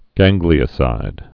(găngglē-ə-sīd)